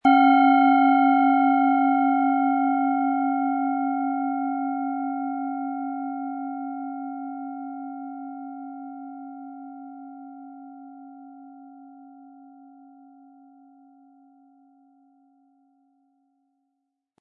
Handgearbeitete tibetische Schale mit dem Planetenton Biorhythmus Körper.
Um den Original-Klang genau dieser Schale zu hören, lassen Sie bitte den hinterlegten Sound abspielen.
Den passenden Schlägel erhalten Sie kostenlos mitgeliefert, er lässt die Klangschale harmonisch und wohltuend ertönen.
SchalenformBihar
MaterialBronze